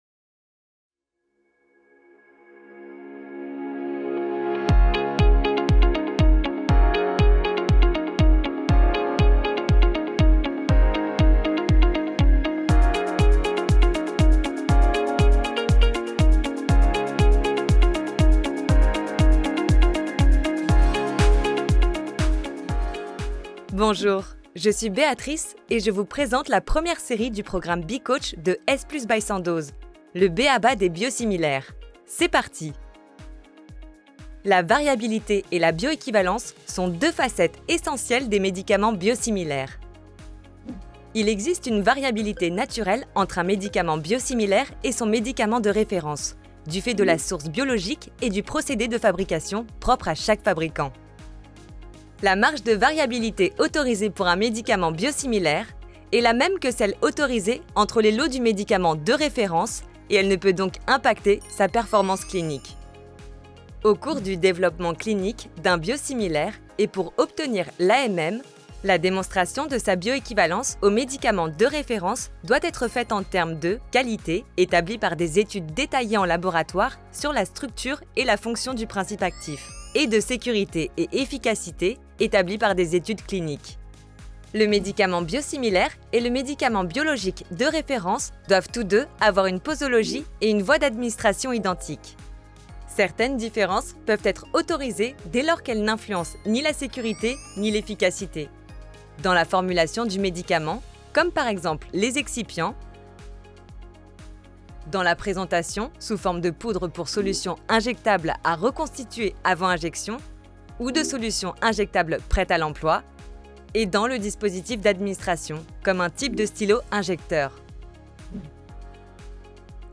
Natural, Llamativo, Versátil, Seguro, Cálida
Audioguía